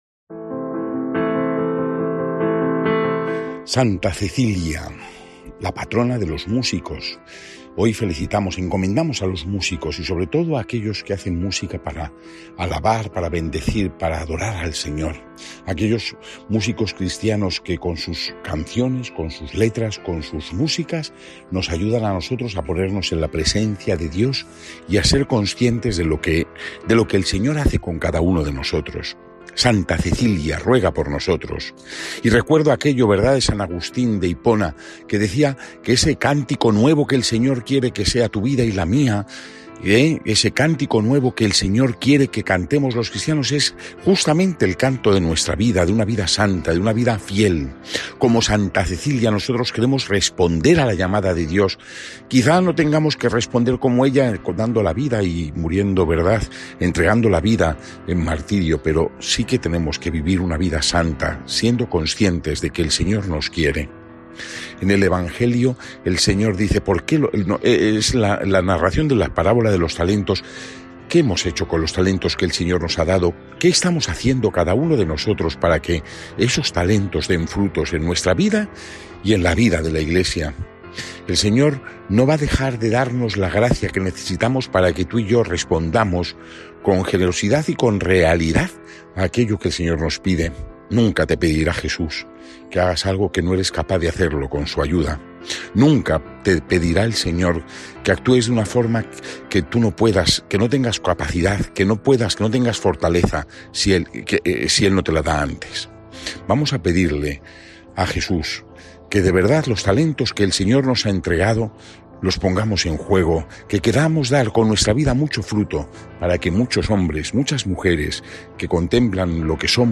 Lectura del santo evangelio según san Lucas 19,11-28